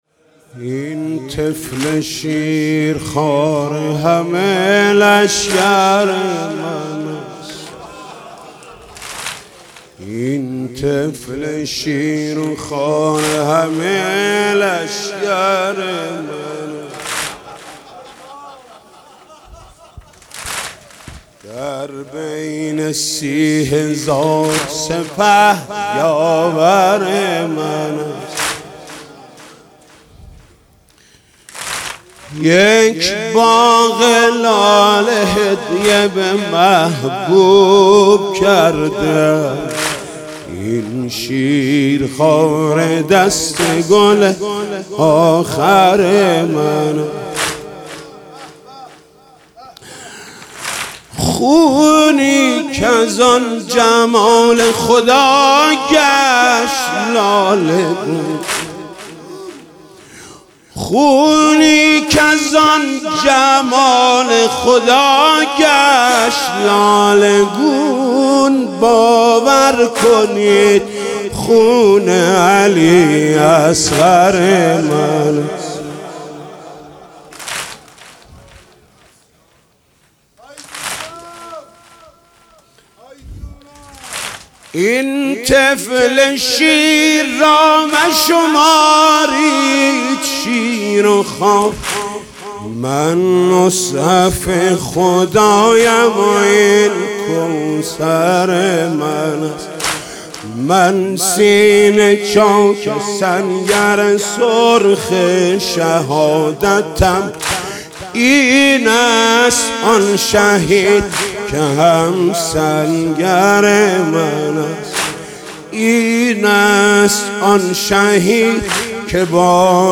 دانلود مداحی این طفل شیرخواره همه لشکر من است محمود کریمی شب هفتم محرم 96